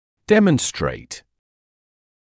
[‘demənstreɪt][‘дэмэнстрэйт]демонстрировать, показывать; проявлять